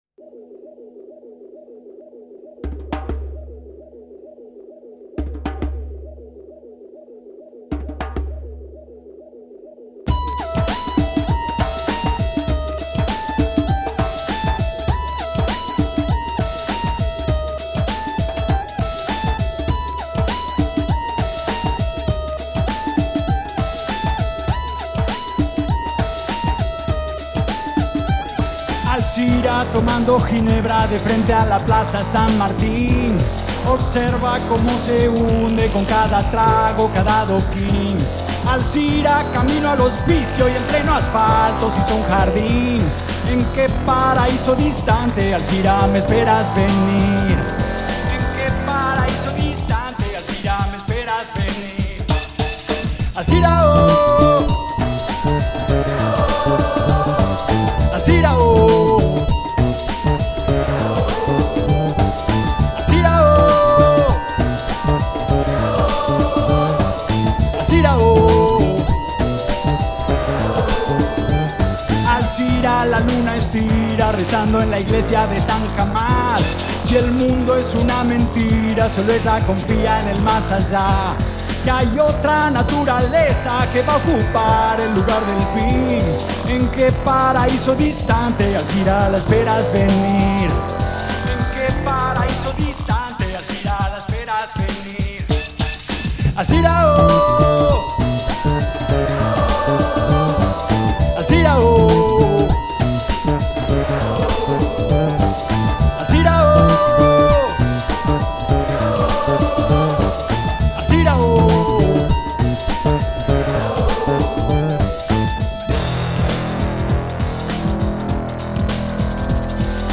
Recorded live in February 2005